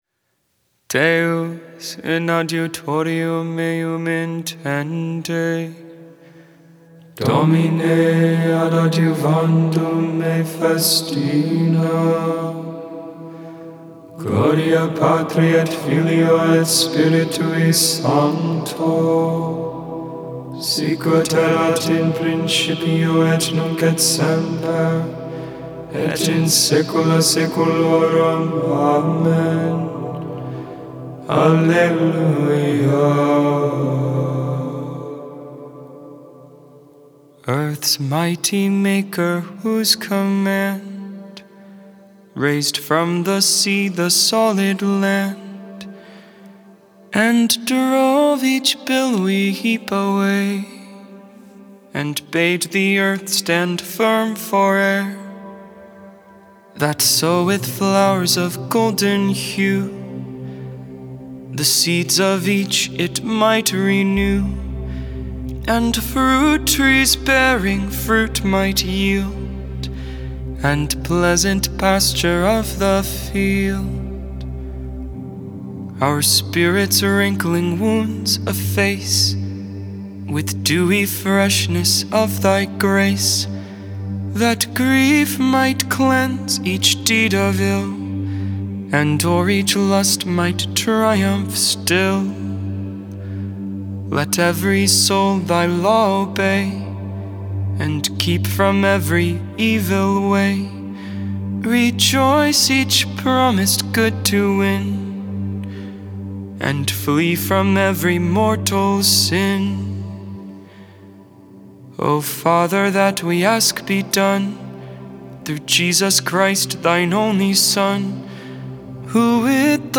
Vespers, Evening Prayer for the 25th Tuesday in Ordinary Time, September 20th, 2022.
Sung in English with the anonymous 19th century.